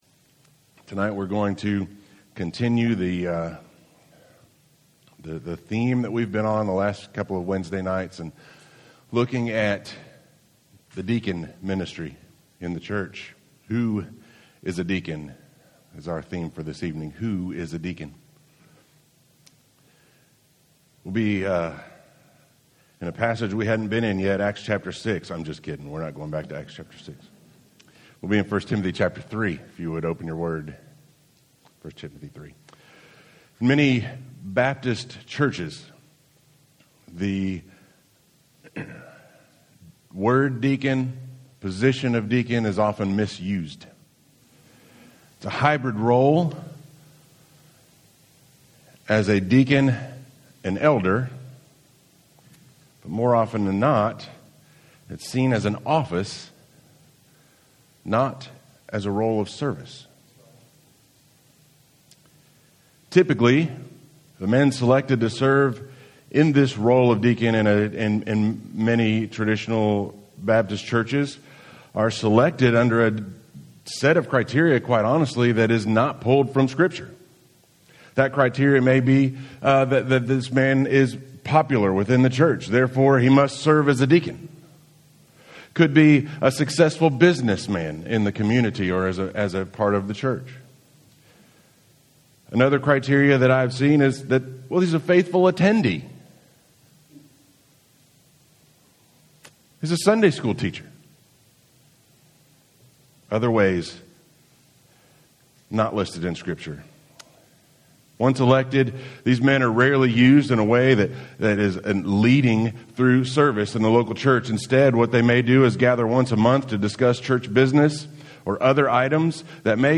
Home › Sermons › Who Is A Deacon